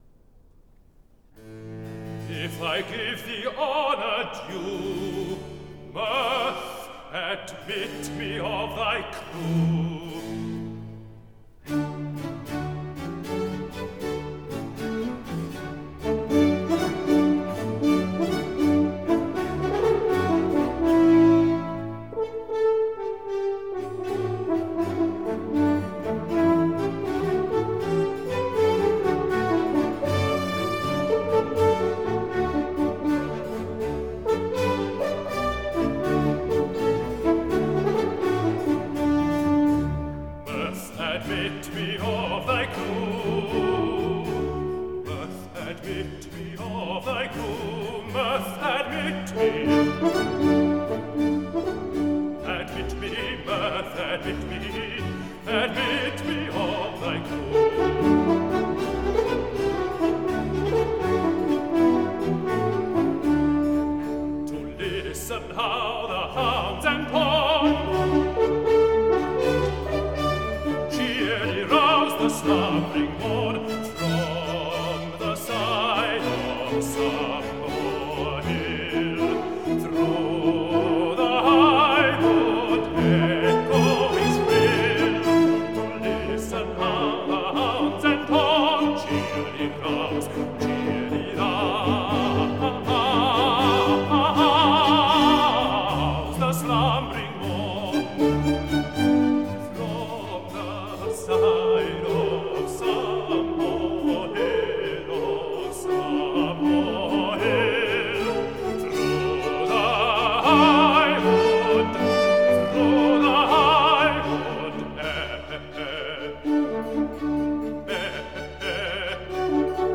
(Air), L’Allegro, il Penseroso ed il Moderato